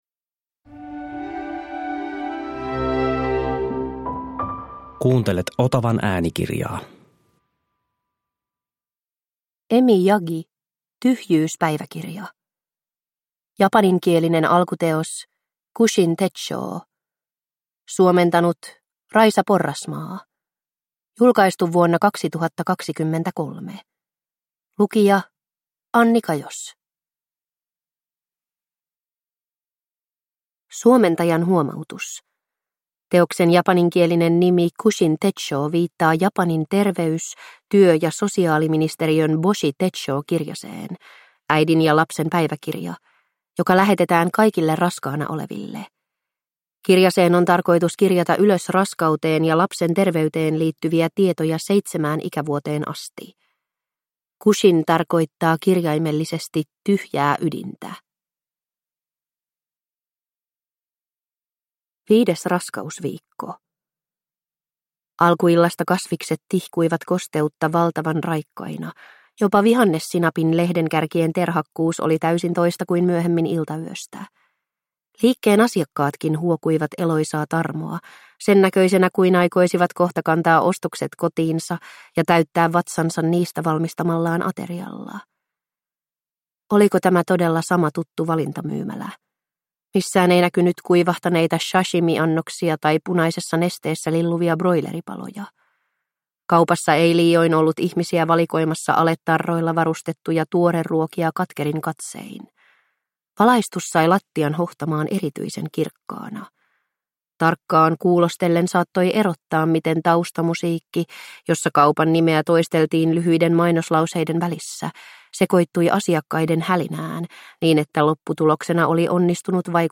Tyhjyyspäiväkirja – Ljudbok